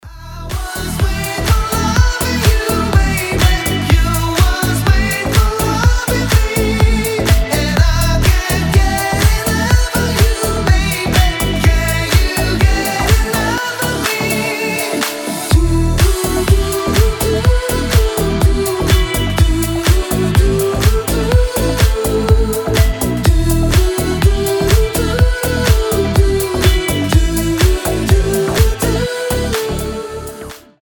deep house
retromix
Club House